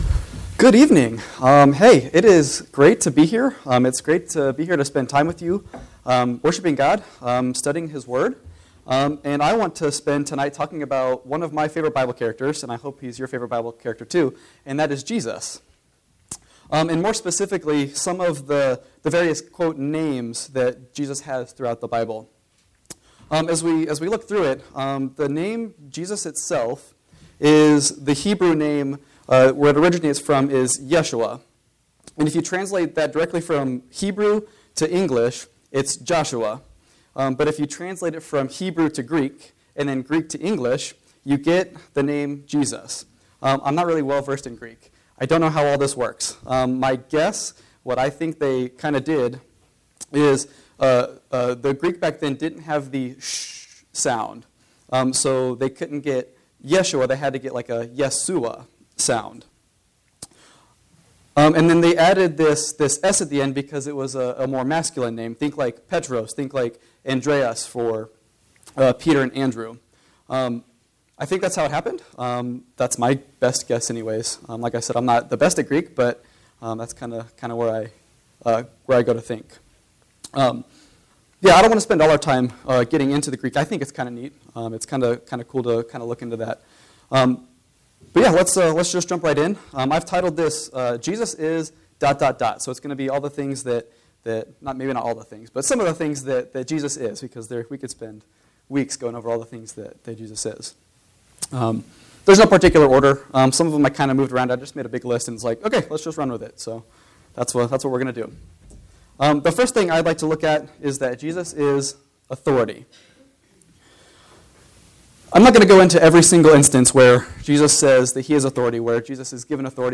Life, Death, and Resurrection of Jesus September 29, 2024 A set of short talks based on the life, death, and resurrection of Jesus Christ.